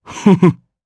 Bernheim-Vox_Happy1_jp.wav